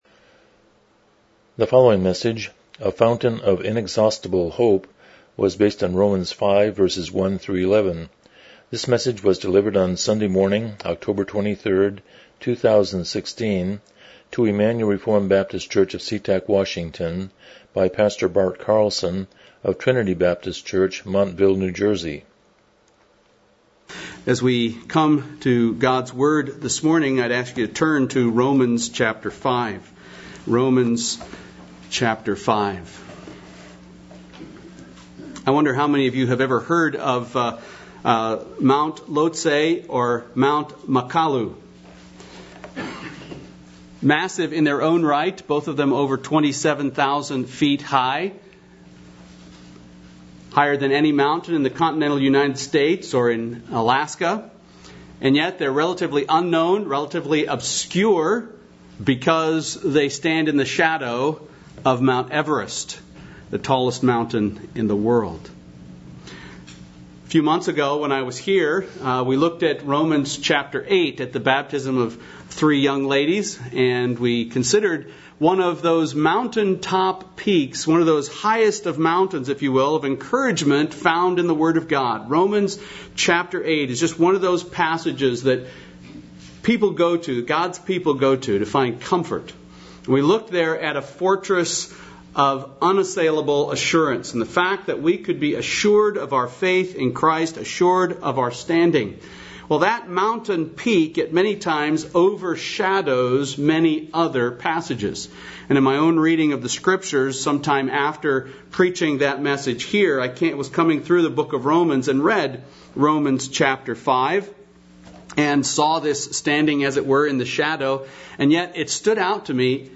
Miscellaneous Passage: Romans 5:1-11 Service Type: Morning Worship « Worldview